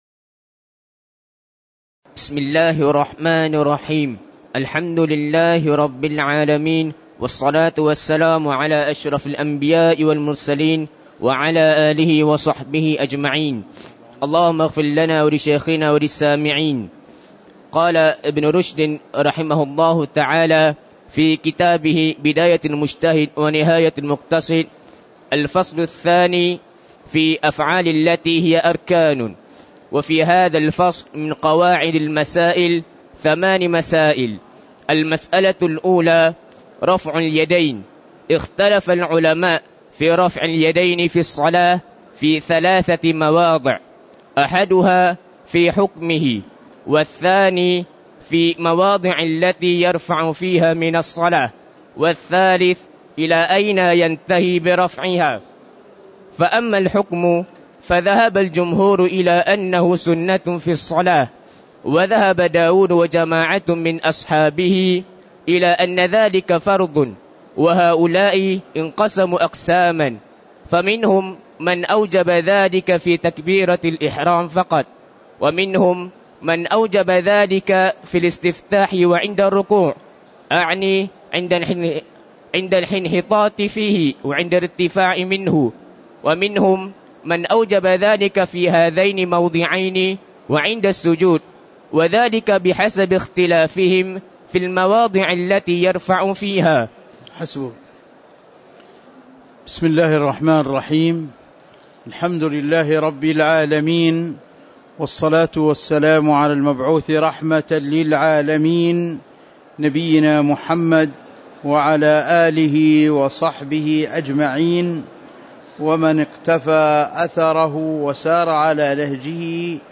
تاريخ النشر ١٥ جمادى الآخرة ١٤٤١ هـ المكان: المسجد النبوي الشيخ